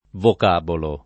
vocabolo [ vok # bolo ] s. m.